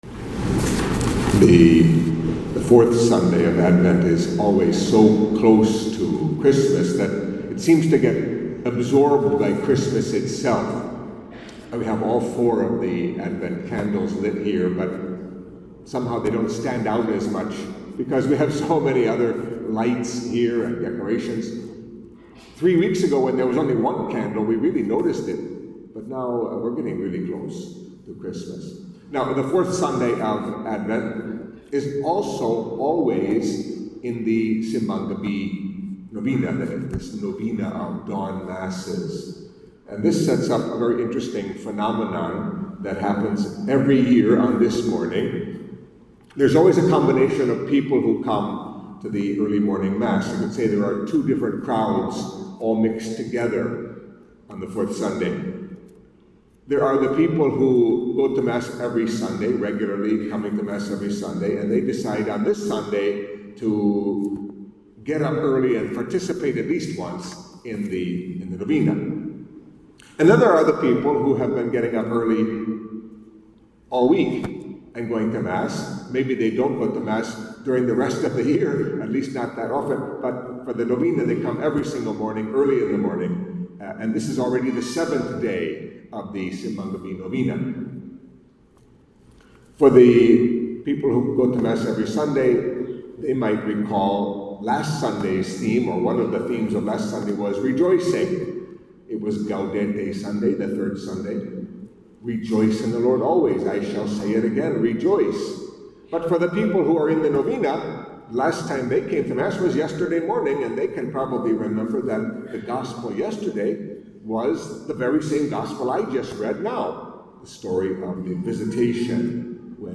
Catholic Mass homily for the Fourth Sunday of Advent